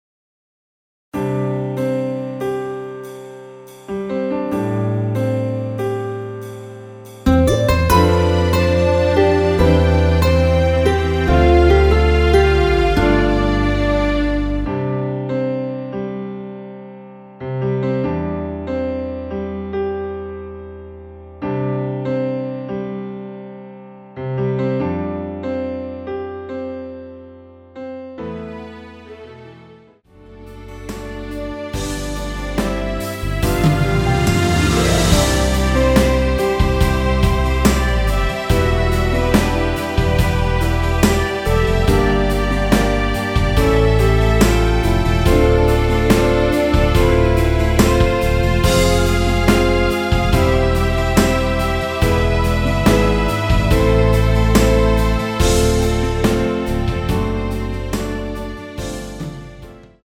원키에서(-2)내린 MR입니다.
◈ 곡명 옆 (-1)은 반음 내림, (+1)은 반음 올림 입니다.
앞부분30초, 뒷부분30초씩 편집해서 올려 드리고 있습니다.
중간에 음이 끈어지고 다시 나오는 이유는